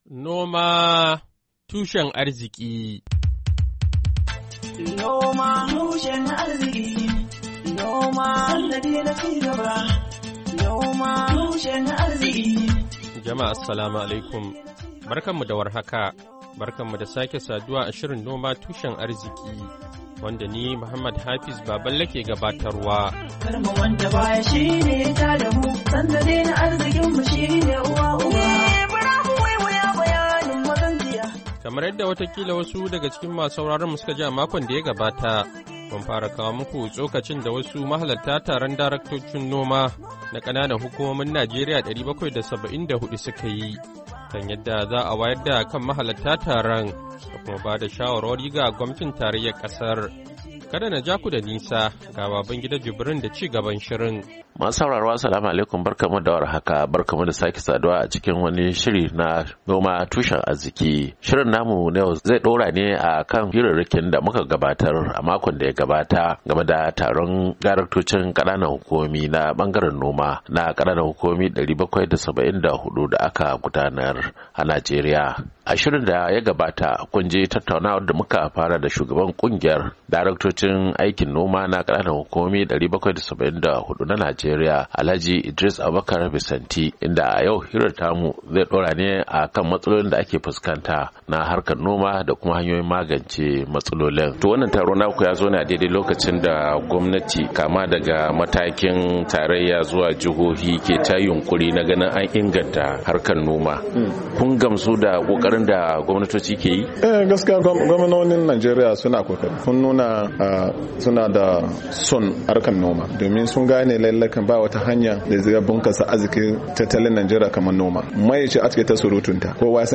Shirin Noma Tushen Arziki na wannan makon, zai kawo muku ci gaban tattaunawa da mahalarta taron daraktocin noma na kananan hukumomi 774 a Najeriya, inda suka tattauna yadda za'a wayar da kan mahalarta taron da kuma ba da shawara ga gwamnatin tarayyar kasar.